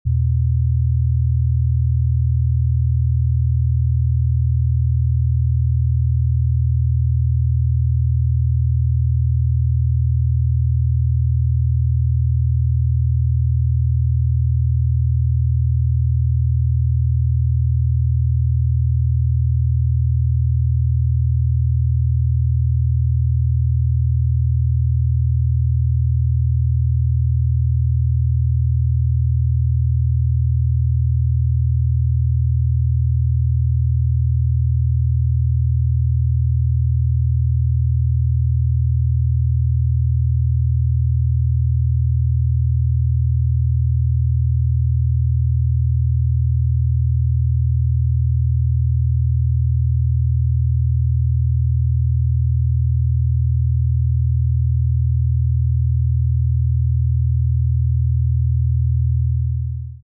40 HZ Gamma Binaural Beats, sound effects free download